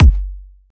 Kick (2).wav